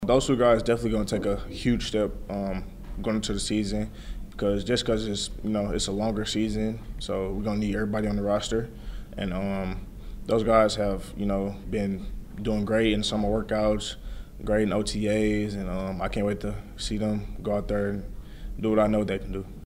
Mizzou player cuts from SEC Media Days.